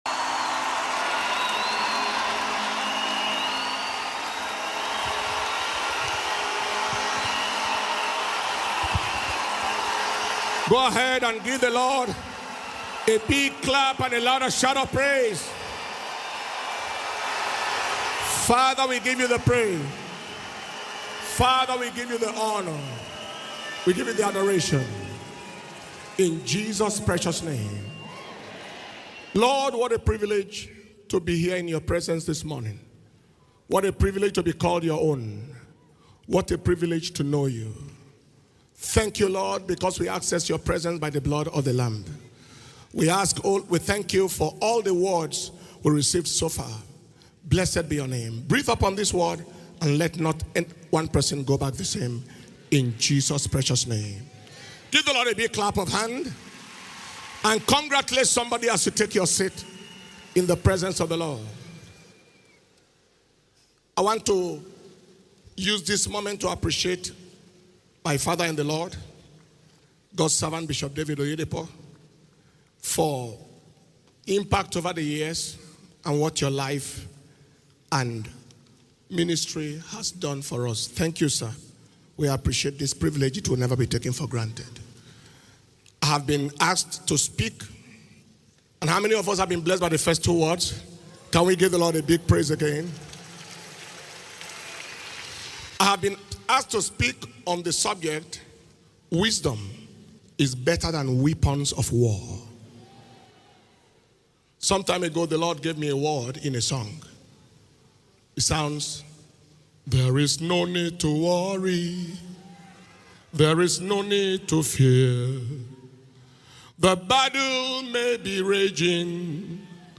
Shiloh 2024